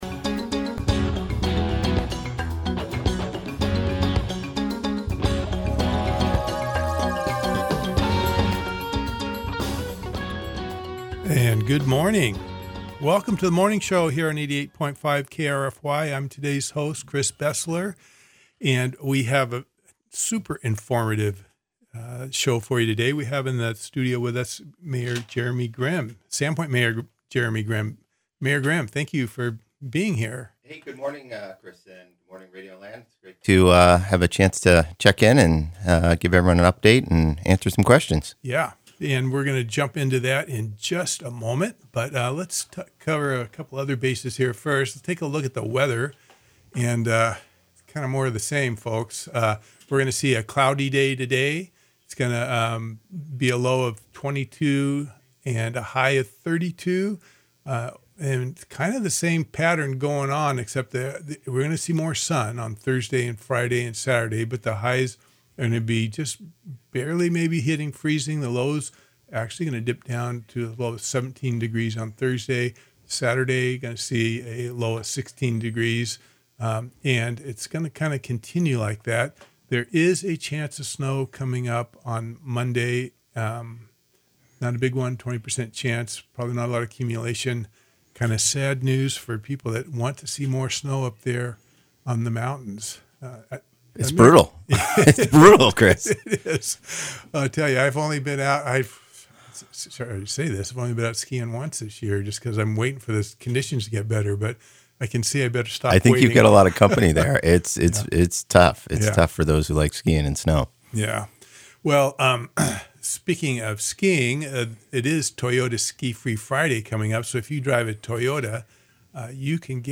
An hour on current events in the city with the mayor of Sandpoint, Jeremy Grimm.